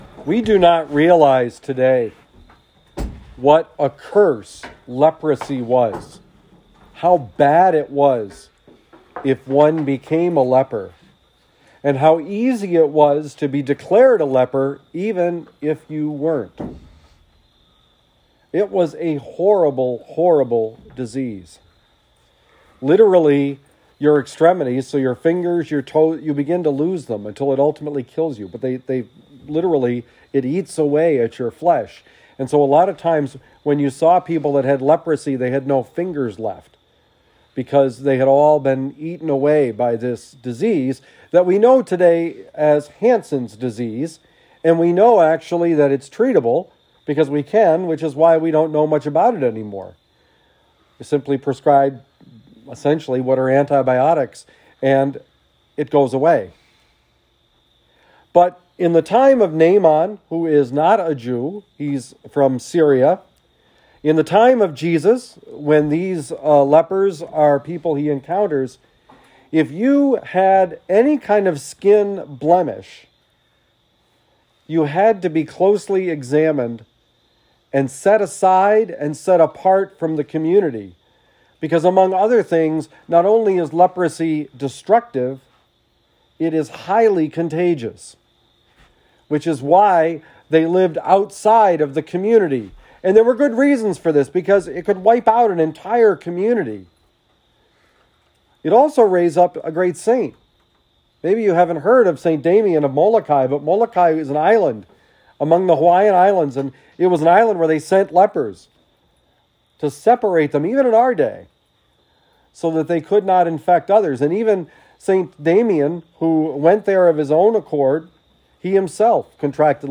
Homily given at Camp Trinity, New Haven, Missouri, for the CBC Brothers in Prayer Retreat.